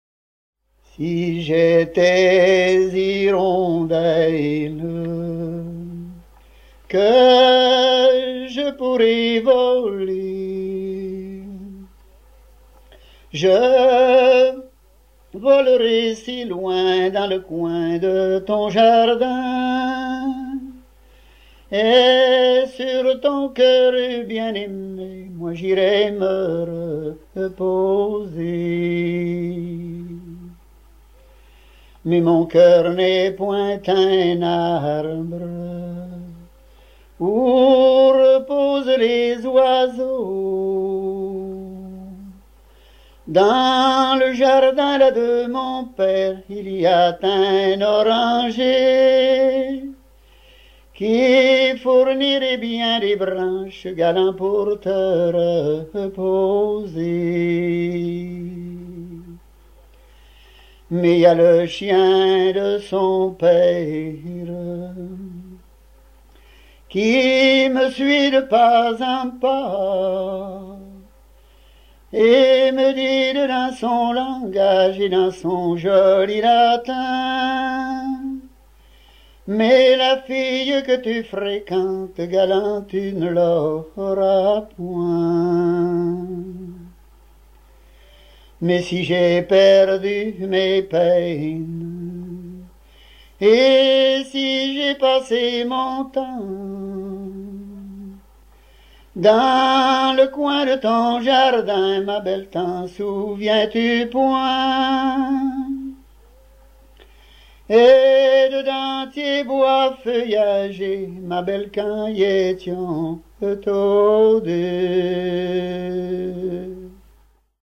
Localisation Falleron
Genre strophique
Pièce musicale éditée